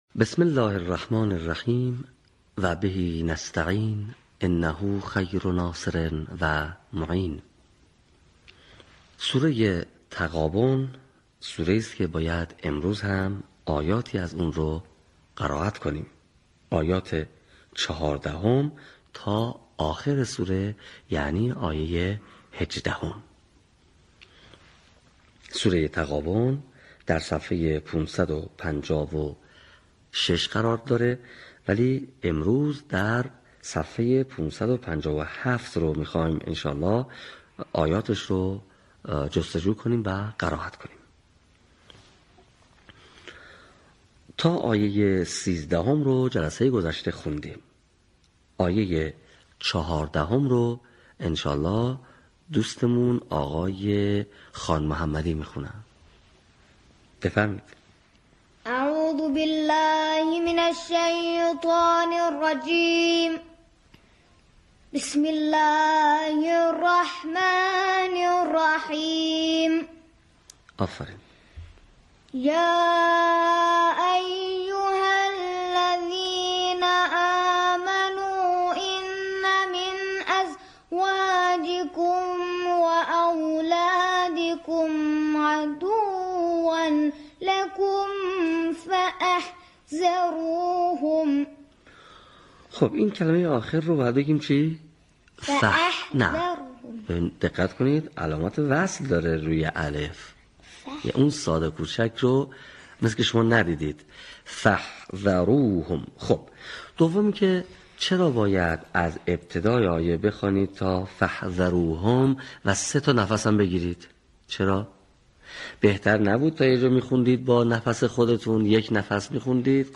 صوت | آموزش قرائت آیات ۱۴ تا ۱۸ سوره تغابن